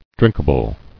[drink·a·ble]